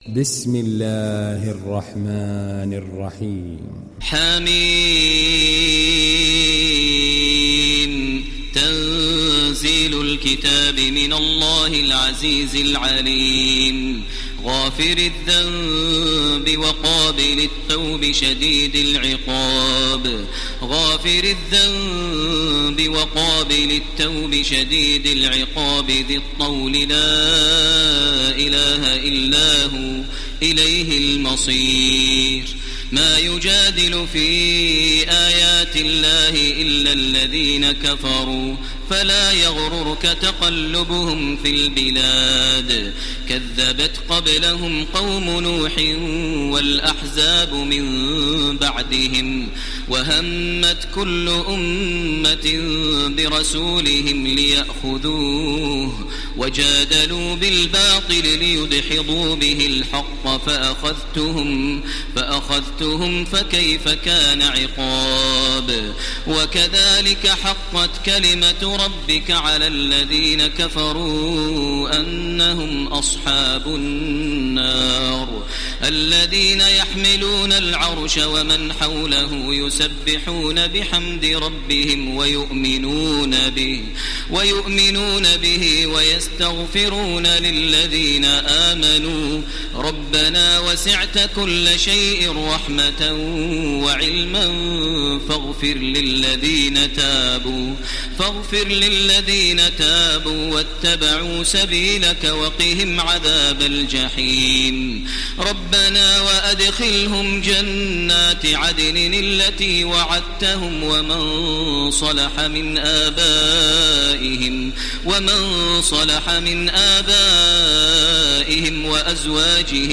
Download Surat Ghafir Taraweeh Makkah 1430